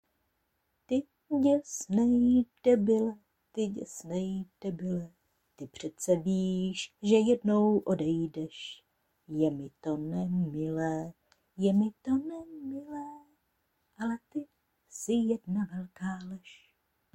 a ty zpiváš stejně jako píšeš, moc pěkně a hravě:-)